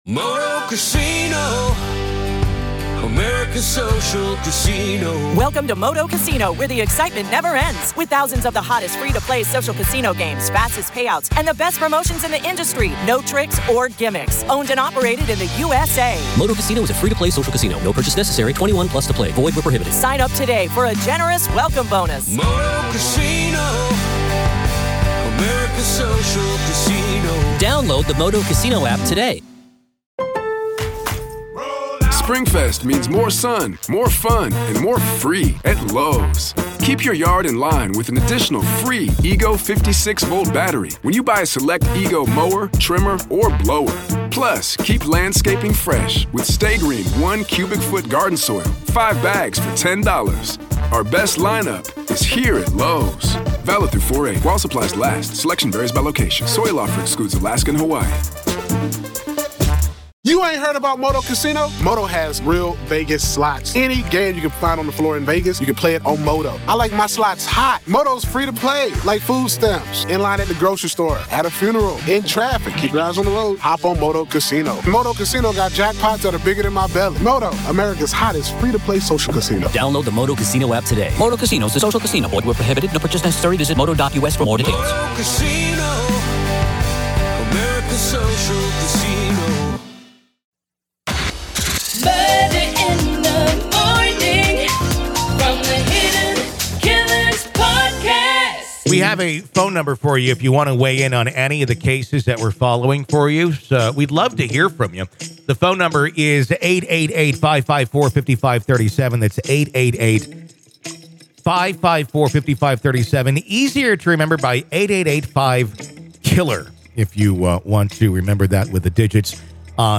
May 17, 2023, 11:00 AM Headliner Embed Embed code See more options Share Facebook X Subscribe In this deeply engaging episode, we delve into the unsettling case of Lori Vallow Daybell, whose actions have provoked widespread shock and perplexity. Our listeners call in, sharing their perspectives and emotions, as we explore the circumstances that led to such a devastating outcome.